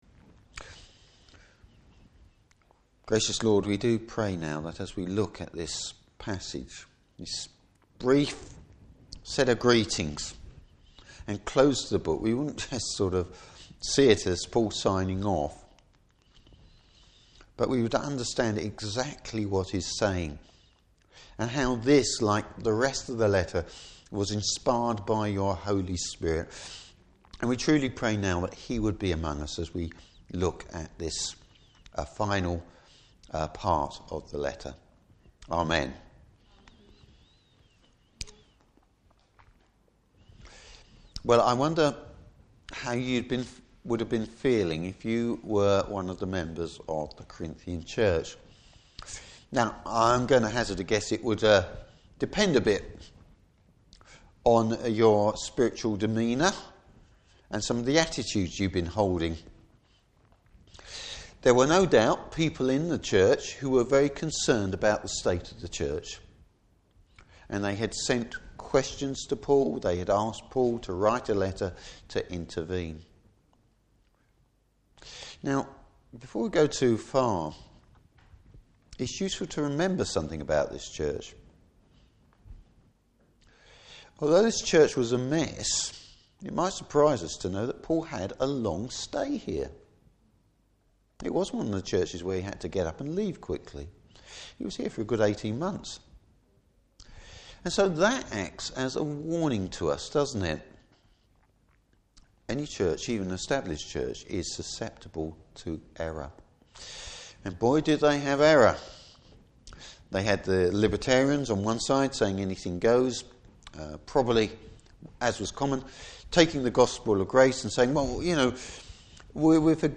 Service Type: Morning Service Paul Finishes on the theme of partnership.